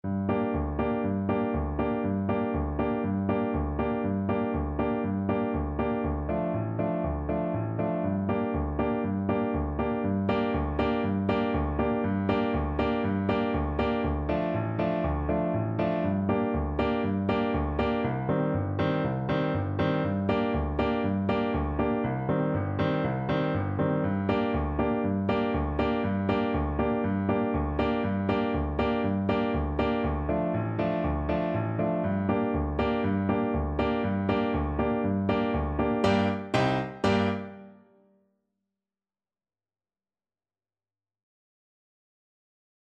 Allegro vivo (View more music marked Allegro)
4/4 (View more 4/4 Music)